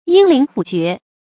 發音讀音
成語注音 ㄧㄥ ㄌㄧㄣˊ ㄏㄨˇ ㄐㄩㄝˊ 成語拼音 yīng lín hǔ jué